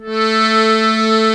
A3 ACCORDI-L.wav